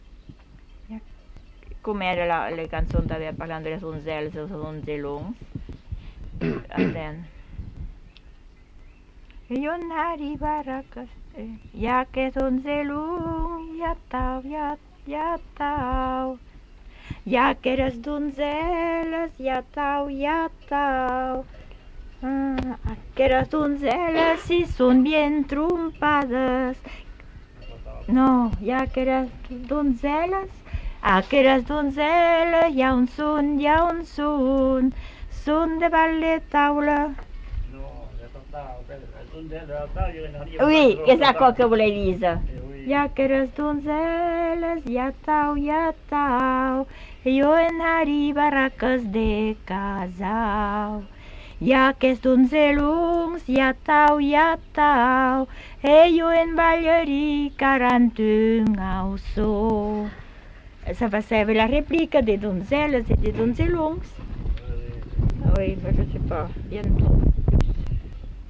Aire culturelle : Petites-Landes
Lieu : Labrit
Genre : chant
Effectif : 1
Type de voix : voix de femme
Production du son : chanté